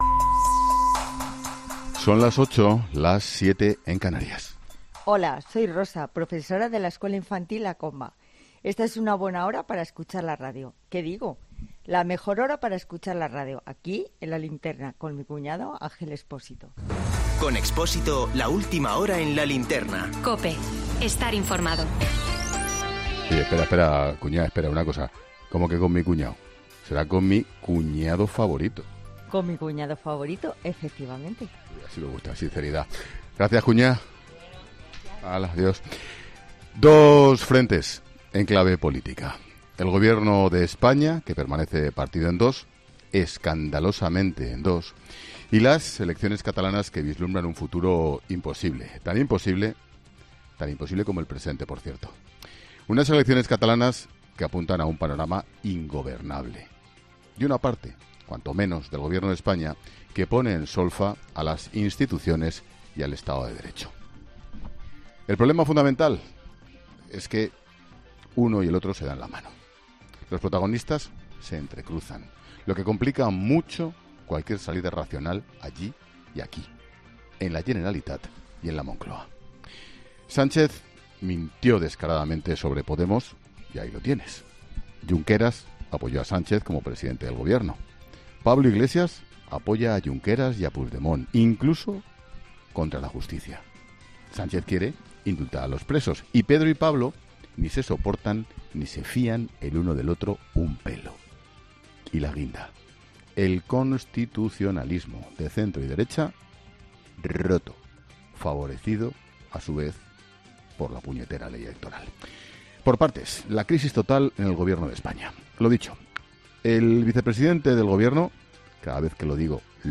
Monólogo de Expósito
El director de 'La Linterna', Ángel Expósito, analiza la situación interna del Ejecutivo y las próximas elecciones catalanas